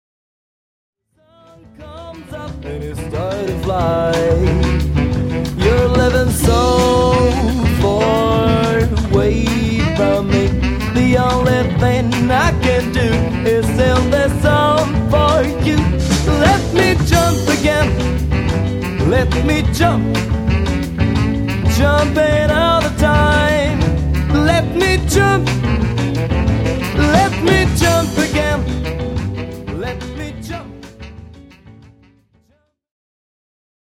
ジャズ、ジャイヴ、スウィング、ボサノヴァといったスタイルに敬意を払いつつも、結果的にはロックなサウンドに仕上がっている。